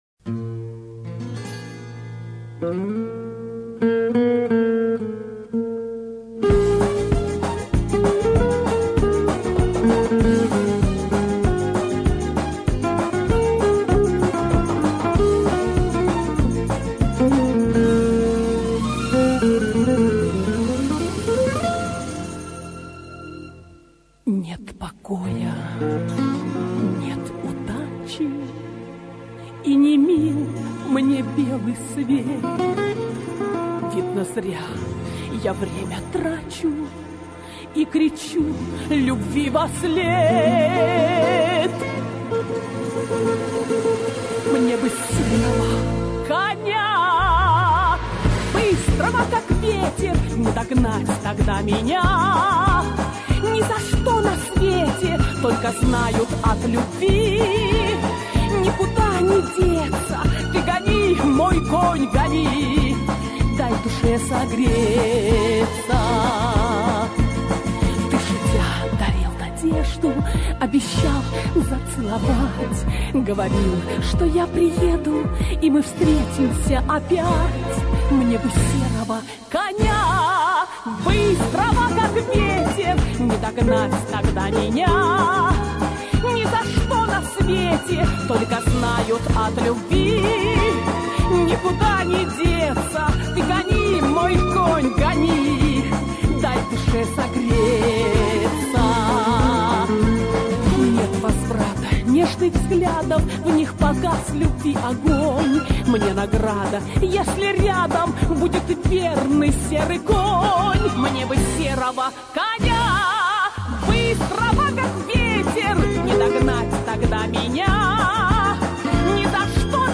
Песня "Серый конь", автор слов Инна Трубочкина, композитор Зинаида Сазонова.